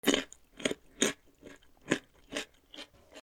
『ボリボリ』